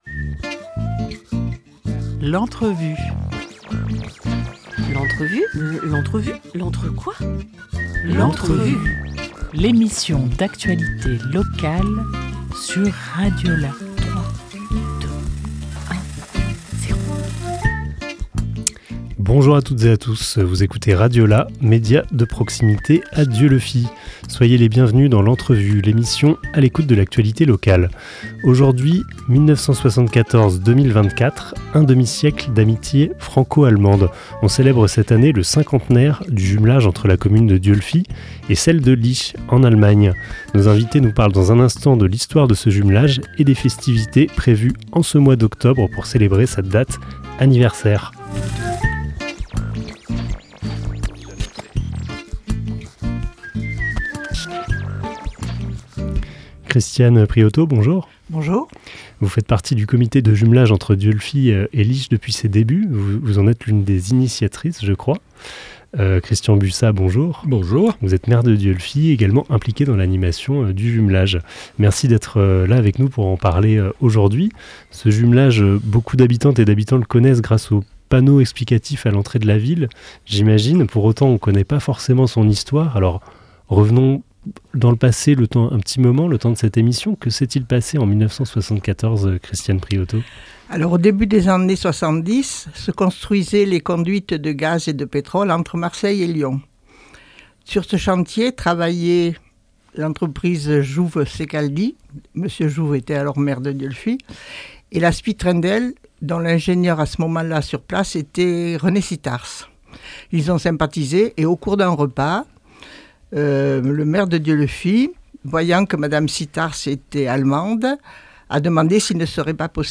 10 octobre 2024 10:51 | Interview